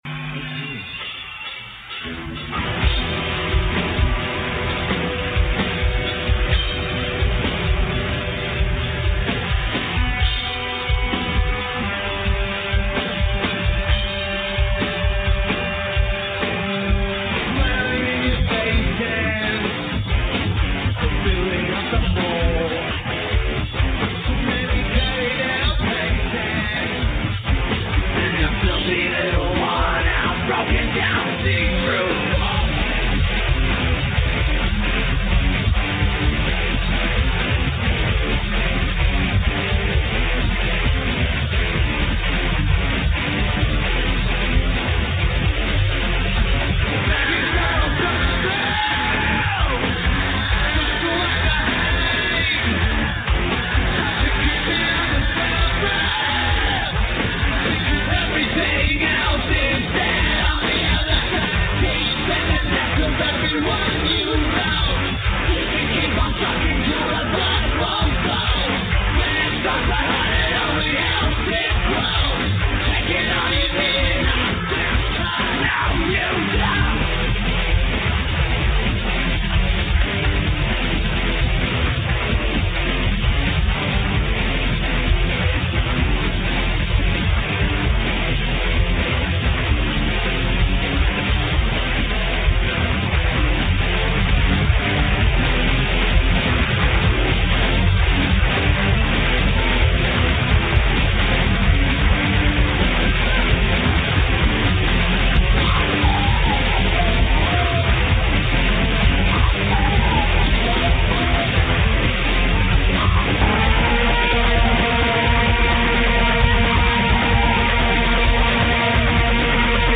RAS Showgrounds
Melbourne Australia
Lineage: Audio - PRO (Soundboard)